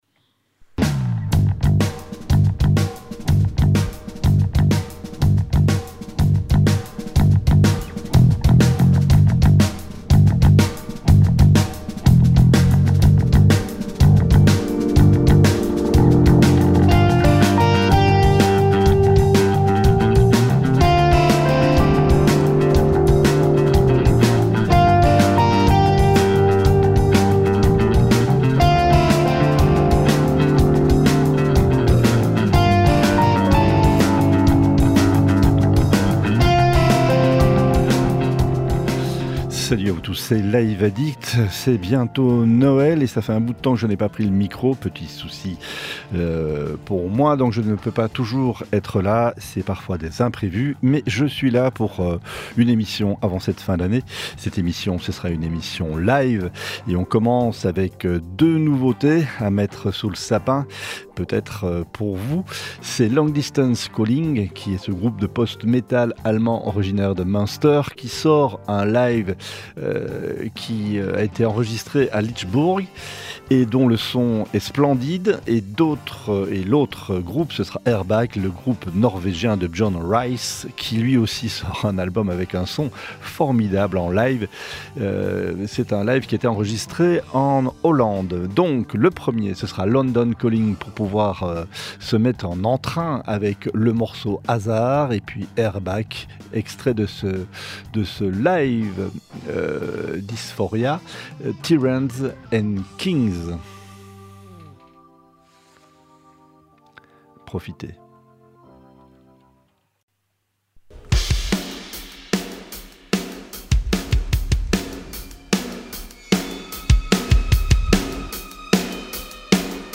rock , rock progressif